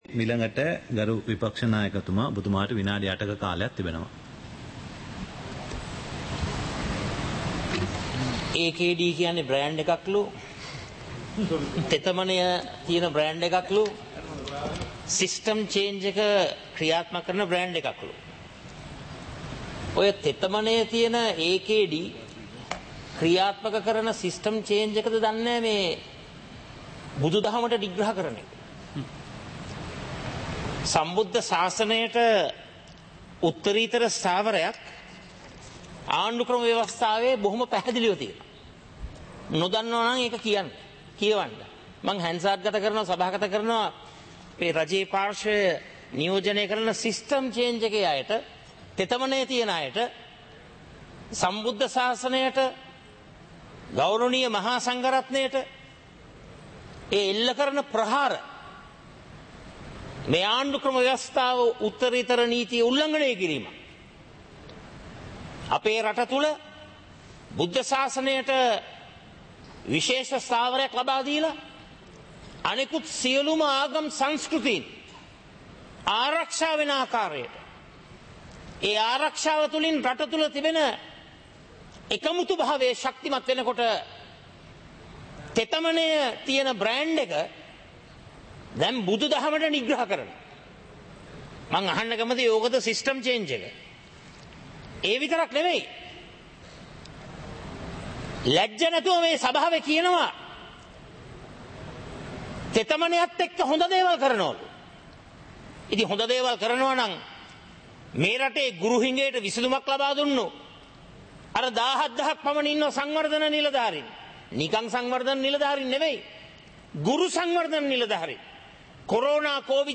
Proceedings of the House (2026-02-03)
Parliament Live - Recorded